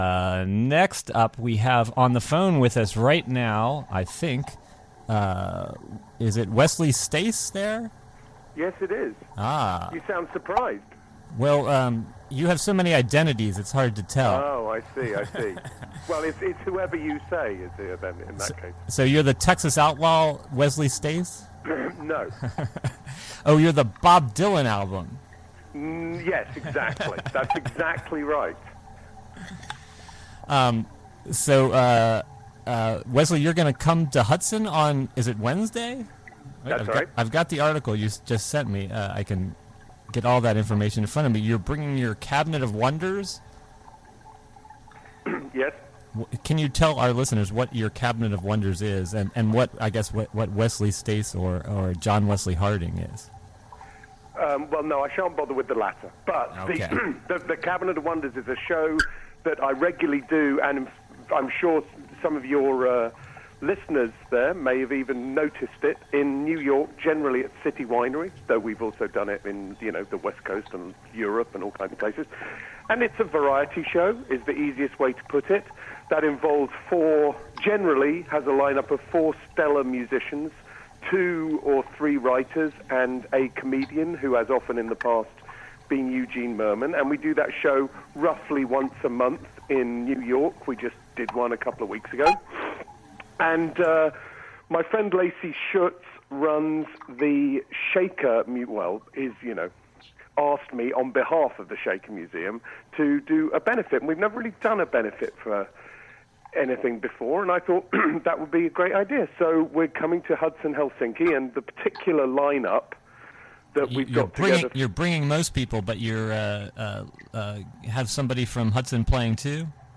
WGXC Morning Show Contributions from many WGXC programmers. broadcasts Wesley Stace : Oct 06, 2017: 9am - 11am In this broadcast, Wesley Stace calls in to talk a...